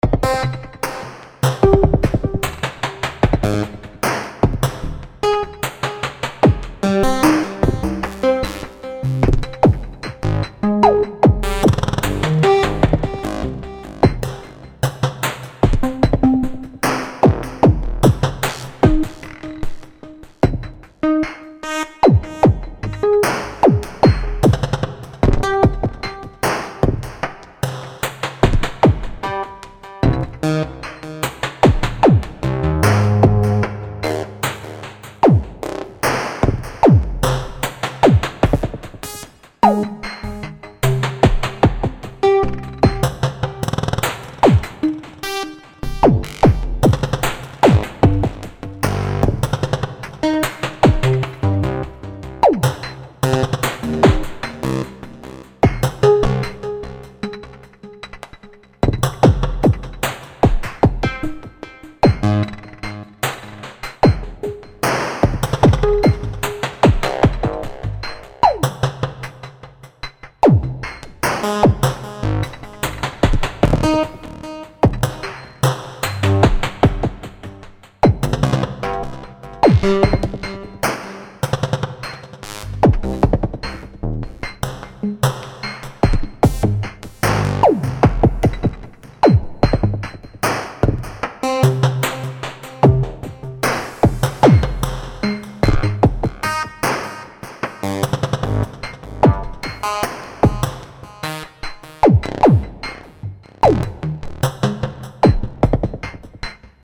My wife was busy, I succeeded to record secretly a one bar loop with lfos tricks and conditional trigs.
A4 directly recorded in OT with its compressor. No live remix, just play and record.
Great drums :slight_smile: Sure.
Kick = 32 x 64, Trig, Exp, Pitch, F1 freq
Snare and “Hihat” = 32 x 128, Trig, Saw, Amp Vol, F2 freq